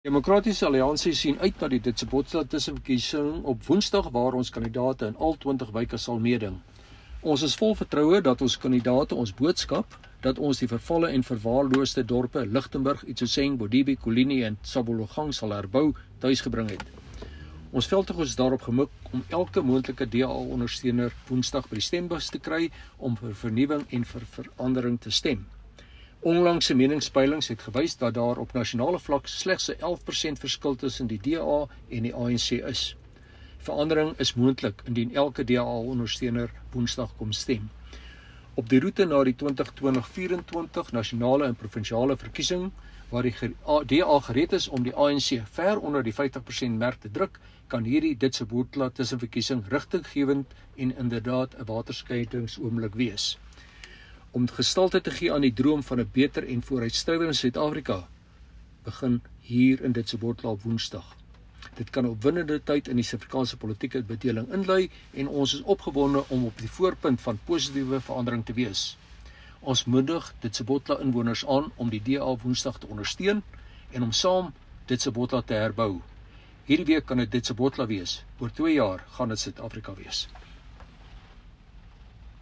Nota aan Redaksie: Vind aangehegte klankgreep in